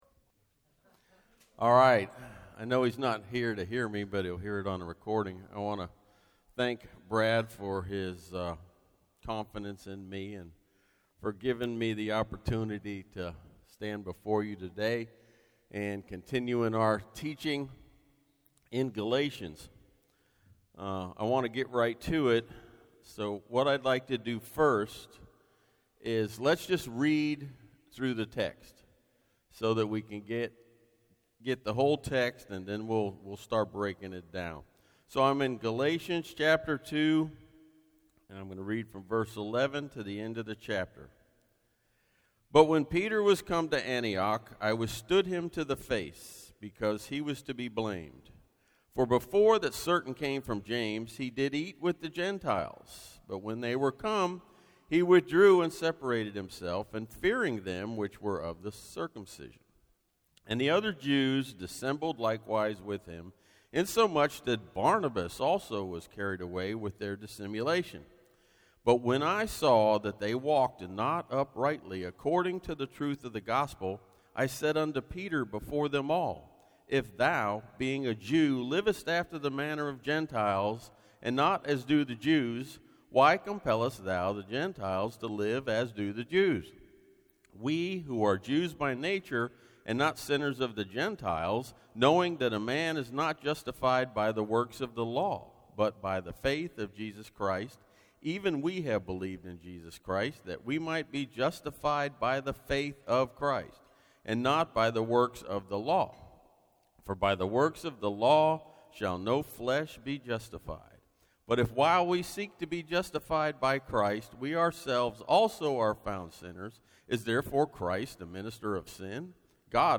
Myrtle Beach – Guest Speaker